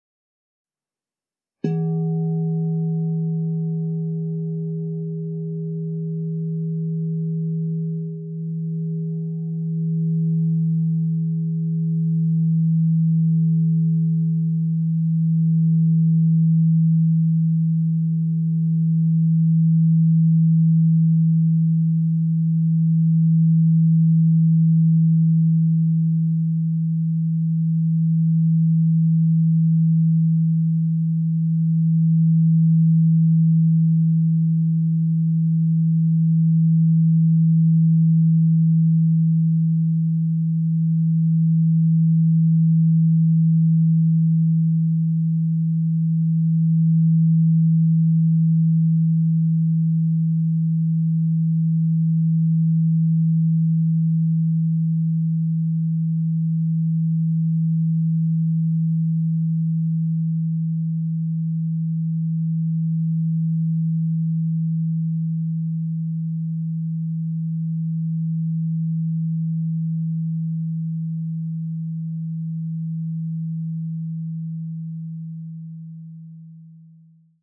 Meinl Sonic Energy 16" white-frosted Crystal Singing Bowl E3, 432 Hz, Solarplexuschakra (CSB16E3)
Produktinformationen "Meinl Sonic Energy 16" white-frosted Crystal Singing Bowl E3, 432 Hz, Solarplexuschakra (CSB16E3)" Die weiß-matten Meinl Sonic Energy Crystal Singing Bowls aus hochreinem Quarz schaffen durch ihren Klang und ihr Design eine sehr angenehme Atmosphäre.